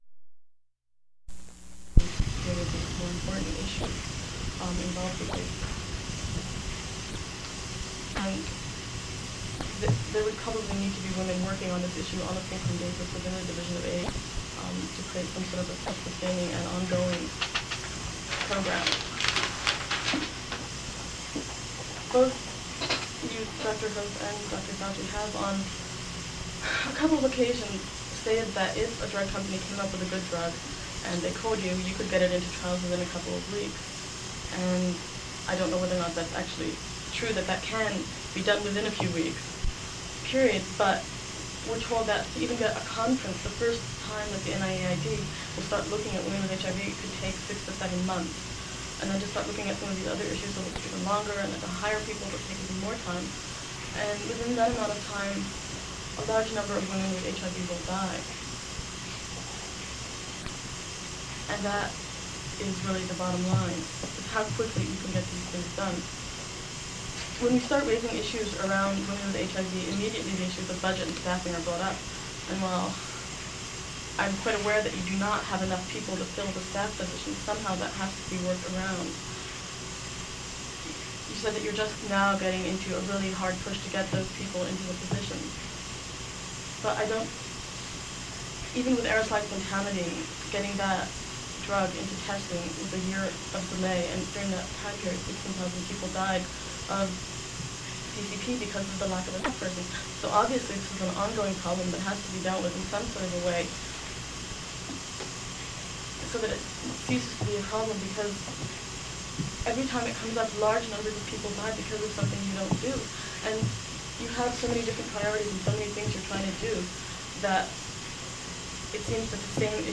Audio cassette
Meetings